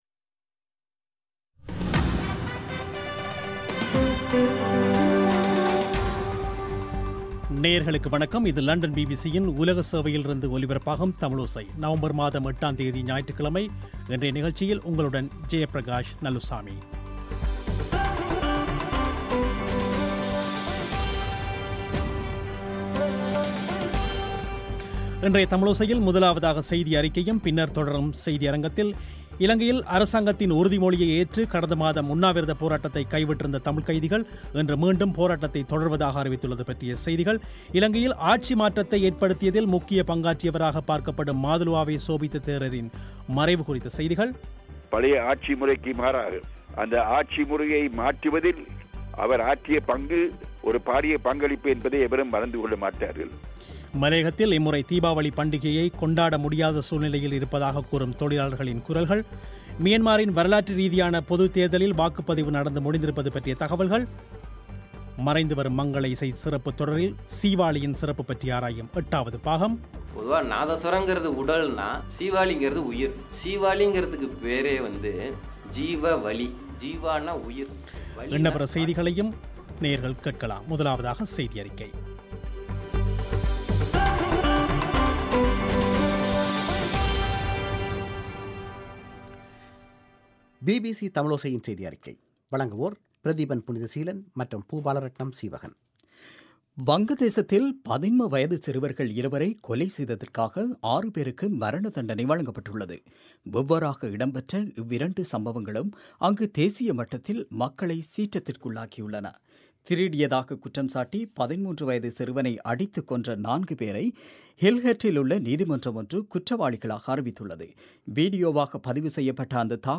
மலையகத்தில் இம்முறை தீபாவளி பண்டிகையை கொண்டாட முடியாத சூழ்நிலைக்கு தள்ளப்பட்டுள்ளதாகக் கூறும் தொழிலாளர்களின் குரல்கள்